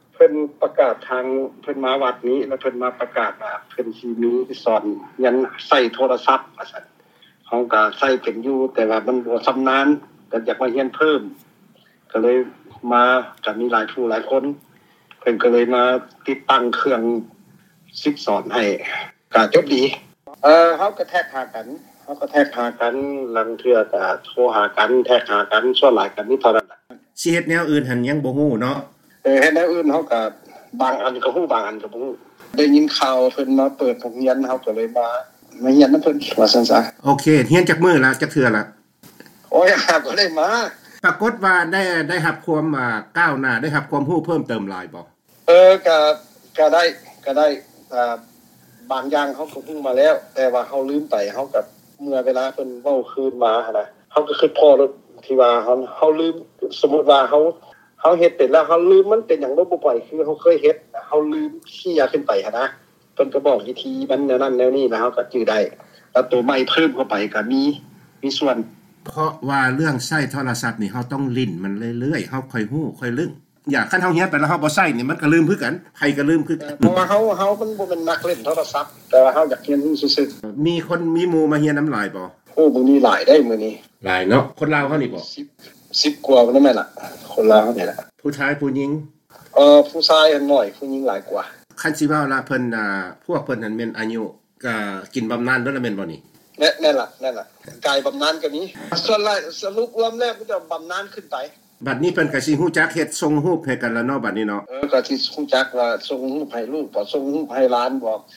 ທ່ານໄດ້ສະແດງຄວາມຮູ້ສຶກດີໃຈທີ່ໄດ້ຄົ້ນພົບ ແລະຮຽນຮູ້ສູ່ ວີໂອເອ ຟັງດັ່ງນີ້: